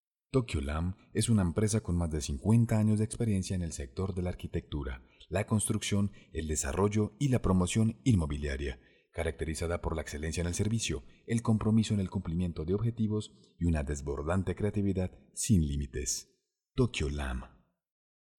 Ingeniero de profesion, capacitado en doblaje y locucion, participacion en fandubs, voz grave natural, con matices e interpretacion para alcanzar varios tonos.
kolumbianisch
Sprechprobe: Industrie (Muttersprache):